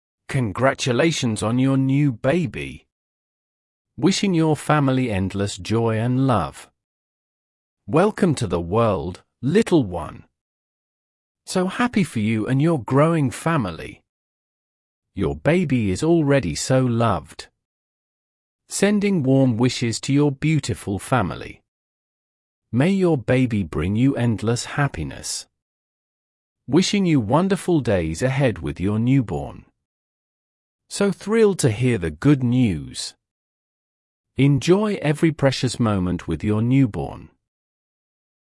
Chúng ta cùng nhau rèn luyện cách phát âm chuẩn từng câu qua